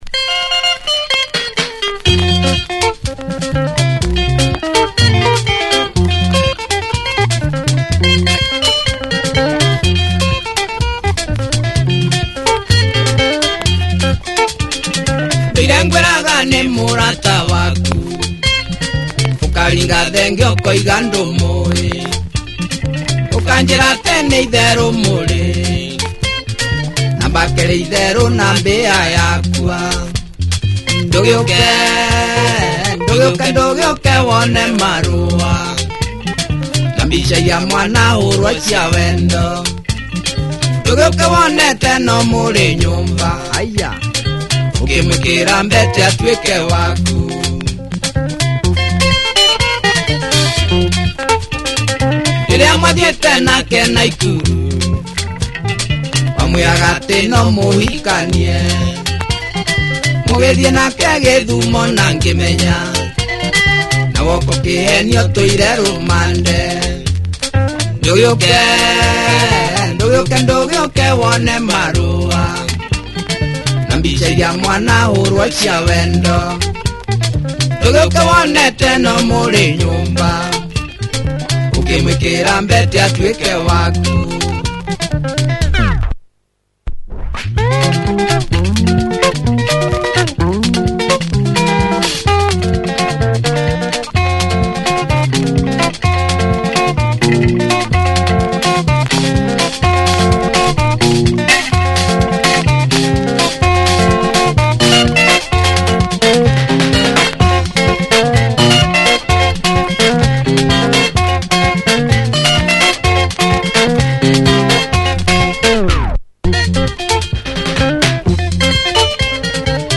Punchy Kikuyu Benga at it’s finest.